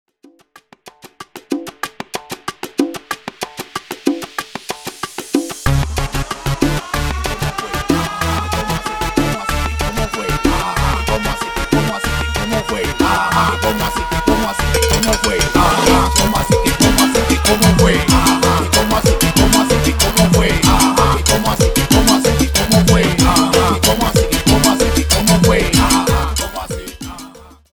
Extended Dirty Intro
Salsa Choke